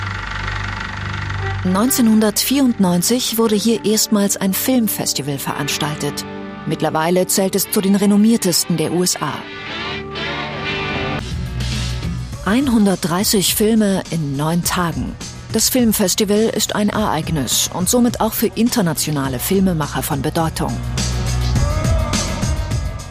Sprechprobe: Industrie (Muttersprache):
german female voice over artist